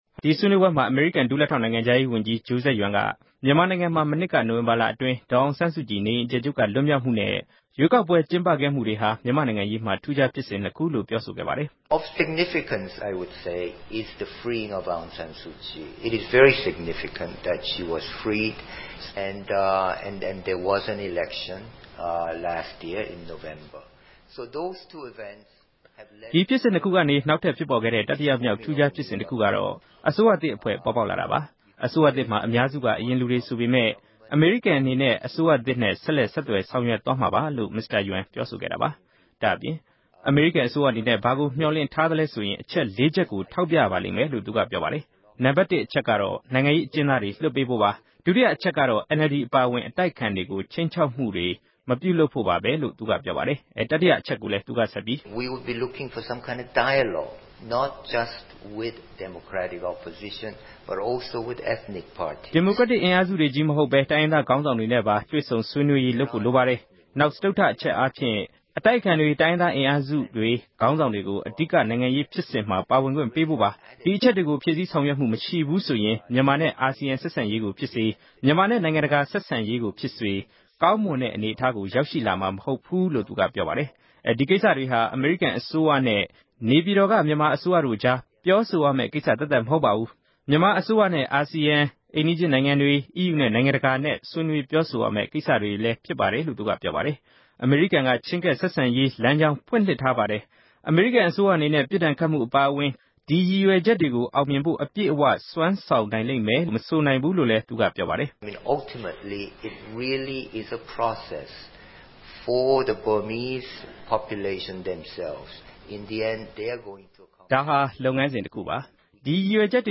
အမေရိကန် ဝါရှင်တန်ဒီစီမြို့တော်က John Hopkins တက္ကသိုလ် နိုင်ငံတကာ အထူးပြု လေ့လာရေးဌာနမှာ မနေ့က ကျင်းပတဲ့ မြန်မာနိုင်ငံနဲ့ ဆက်ဆံနေတဲ့ တောင်နဲ့ မြောက်ကိုးရီးယားနိုင်ငံတို့ရဲ့ ရည်ရွယ်ချက် ခေါင်းစဉ်နဲ့ ဆွေးနွေးပွဲမှာ မစ္စတာ Yun က အခုလို ပြောဆိုခဲ့တာပါ။